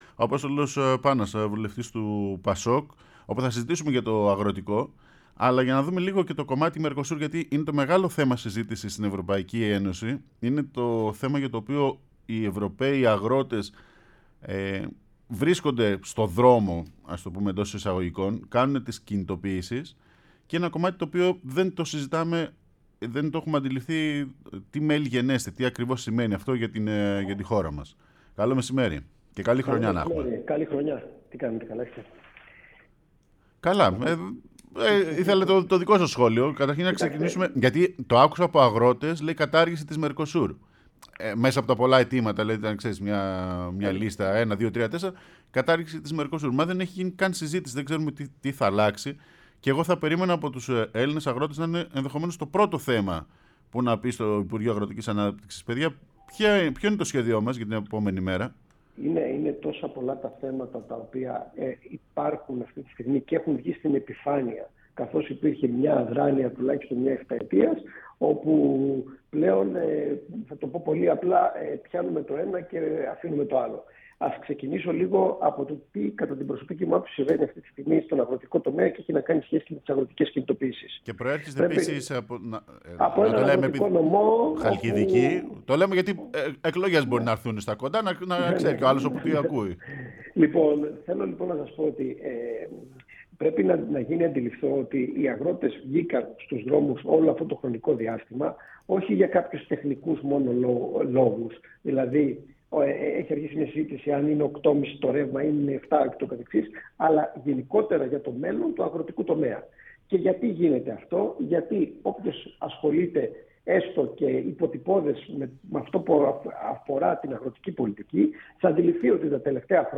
βουλευτής ΠΑΣΟΚ μίλησε στην εκπομπή Έχουμε και λέμε